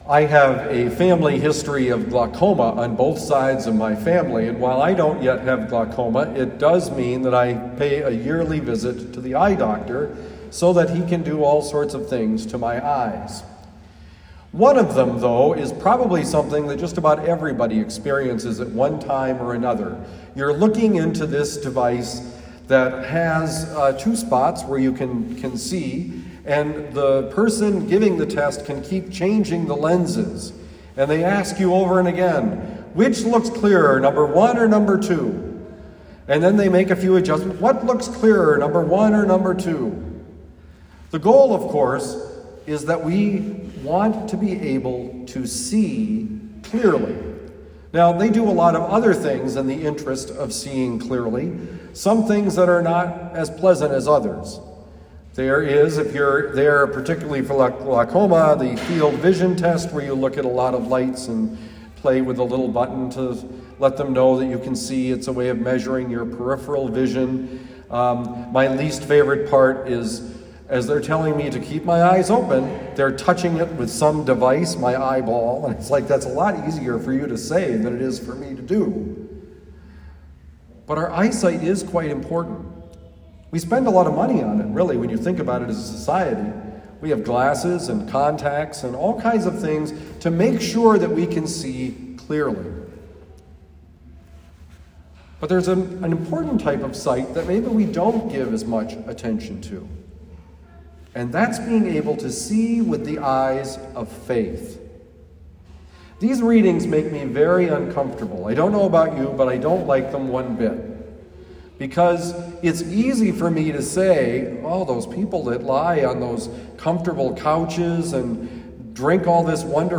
Homily given at Our Lady of Lourdes, University City, Missouri.